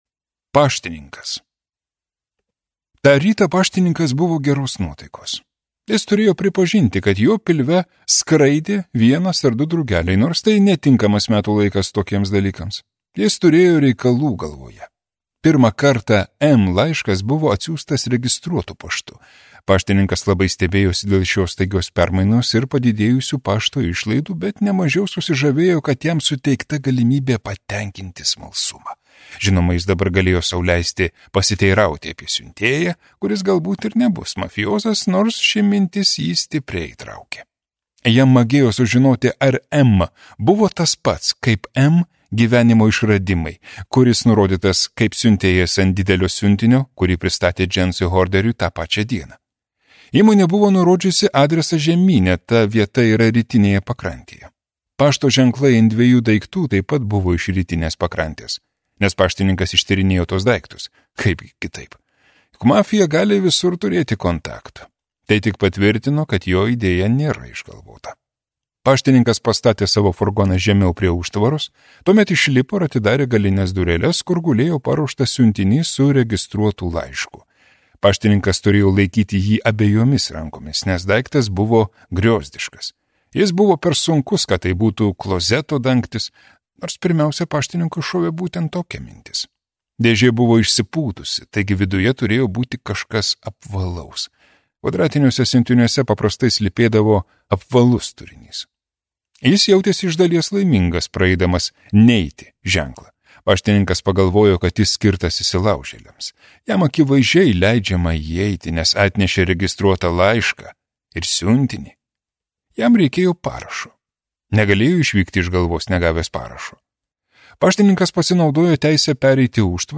Audio knyga